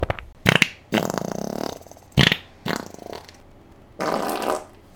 Sugar Free Twizzler Fart
twizzler-sugar-free-fart.mp3